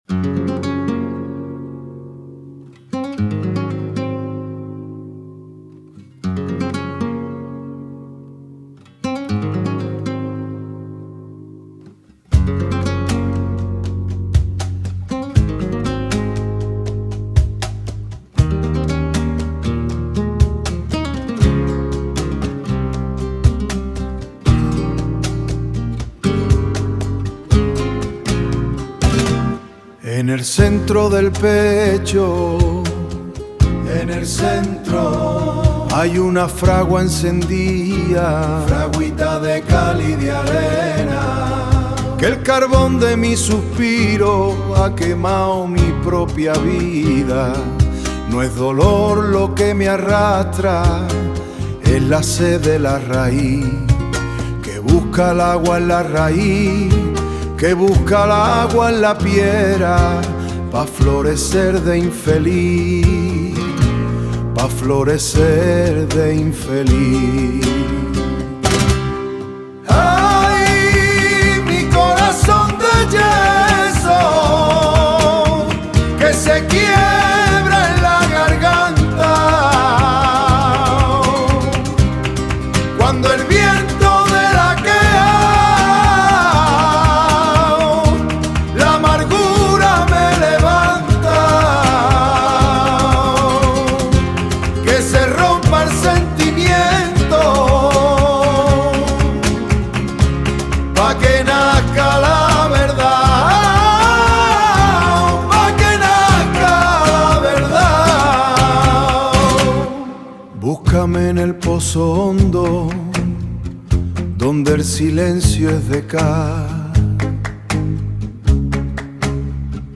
Acabo de realizsr una canción echa exclusivamente con inteligencia artificial 🙄